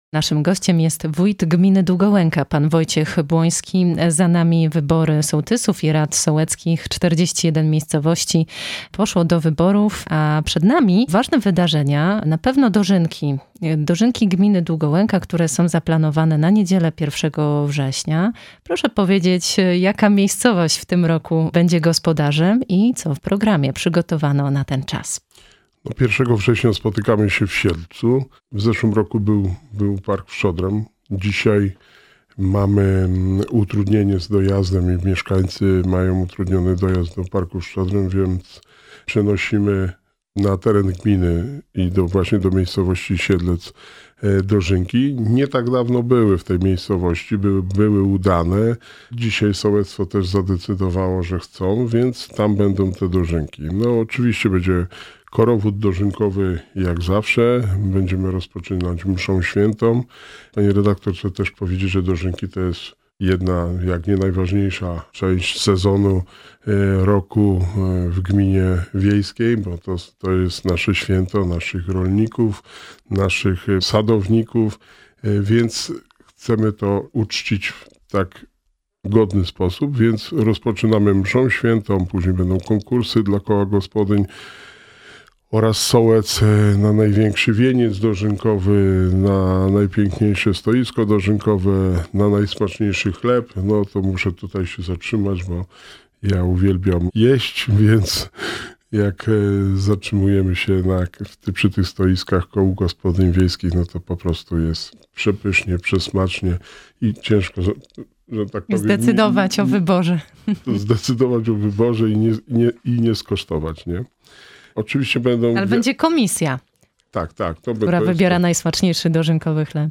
Wojciech Błoński, wójt Gminy Długołęka